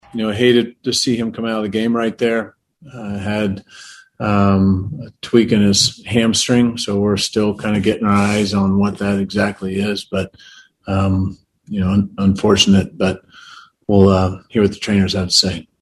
Mondesi was removed in the bottom of the eighth inning with a tweak in his left hamstring, he was working with the Royals trainers postgame. Manager Mike Matheny called it unfortunate.